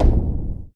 MB Hit (7).wav